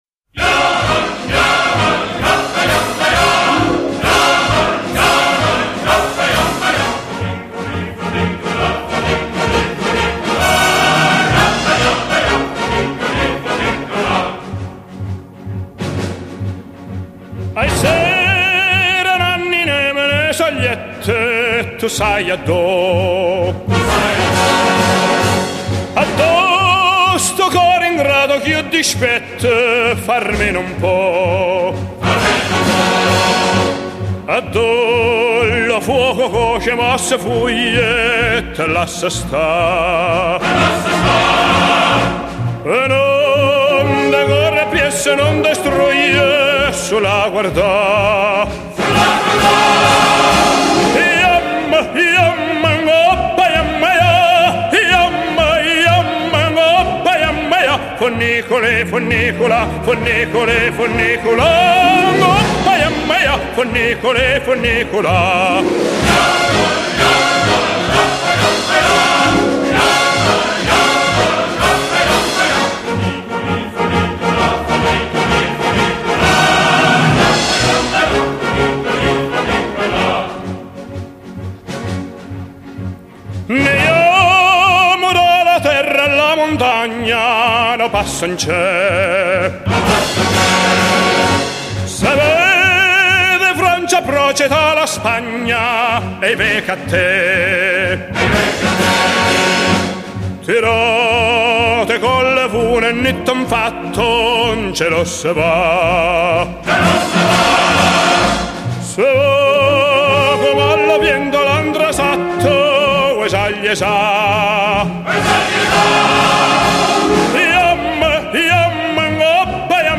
由于原录音是1962